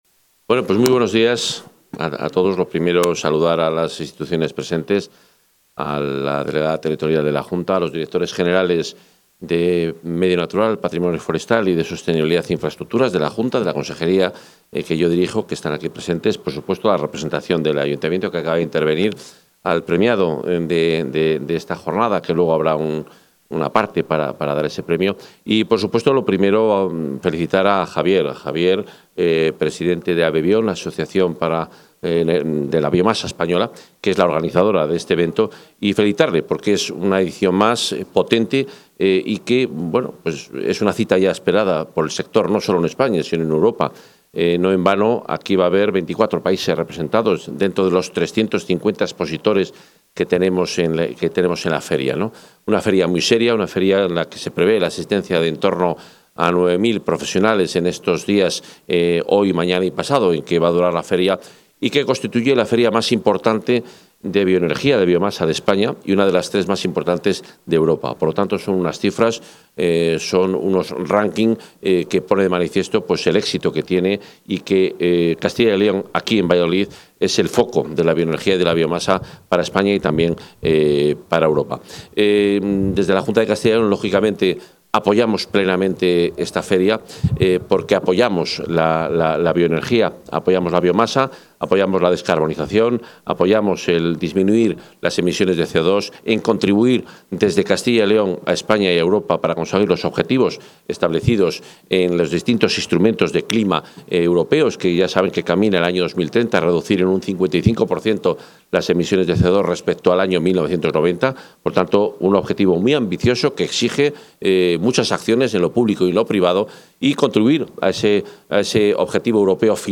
Intervención del consejero.
Durante la inauguración de la feria Expobiomasa 2025 en Valladolid, el consejero de Medio Ambiente, Vivienda y Ordenación del Territorio, Juan Carlos Suárez-Quiñones, ha reafirmado el compromiso de la Junta con la biomasa como elemento clave de la economía circular y la sostenibilidad energética, con inversiones superiores a los 230 millones de euros para desarrollar más de 200 kilómetros de redes de calor sostenibles en diversas ciudades de la Comunidad.